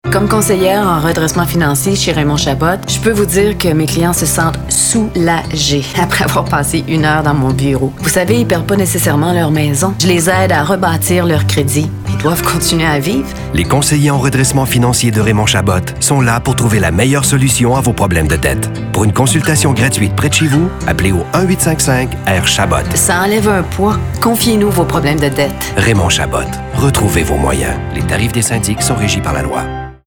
Pour Raymond Chabot, leader de cette industrie au Québec, nous avons plutôt adoptés un ton calme, respectueux et qui invite à la confidence.
Radios